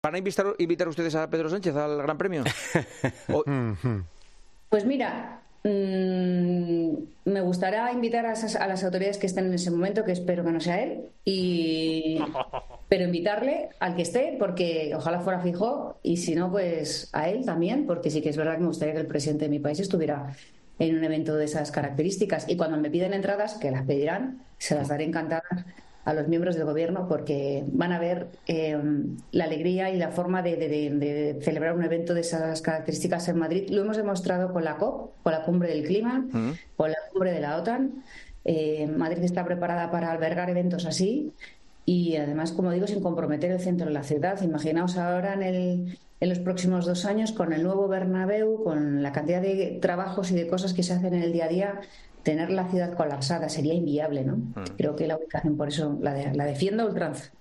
Las autoridades del país no siempre suelen acudir a estos eventos, pero la presidenta de la Comunidad de Madrid contesta a Juanma Castaño, siempre que siga en este puesto